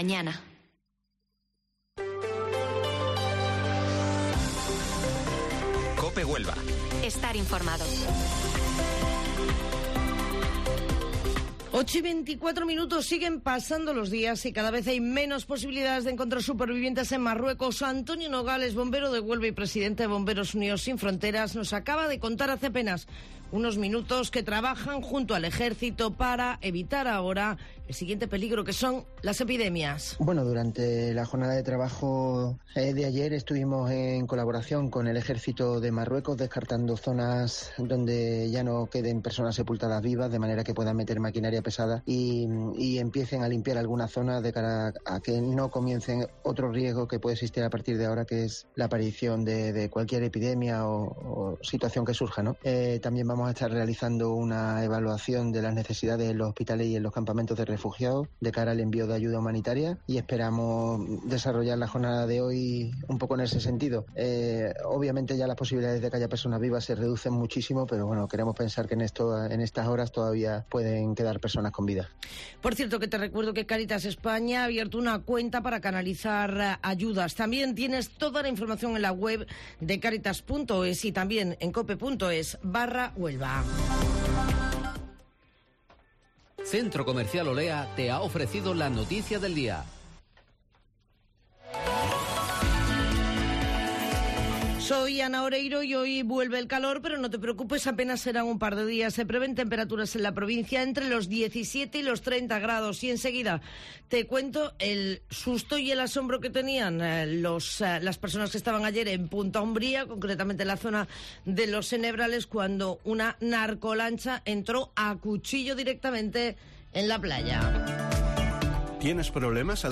Informativo Matinal Herrera en COPE 12 de septiembre